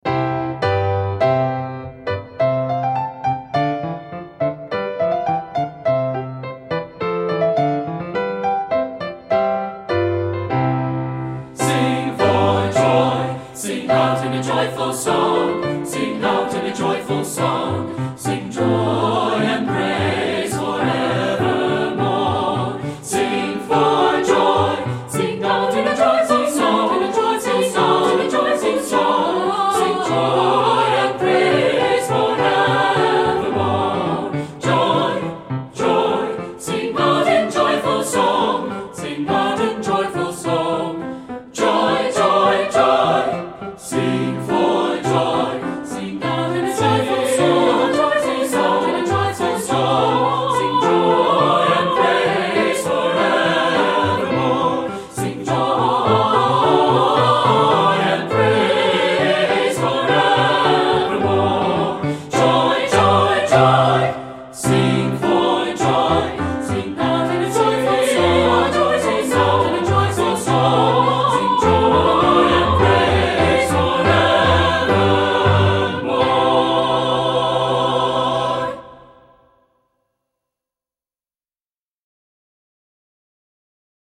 een duet
in een vrolijk en opgewekt arrangement